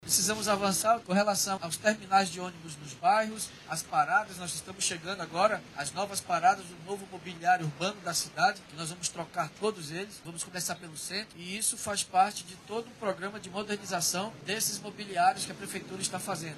Durante a entrega dos novos veículos, o prefeito de Manaus, Davi Almeida, disse que vai reformar as estações e paradas de ônibus da cidade.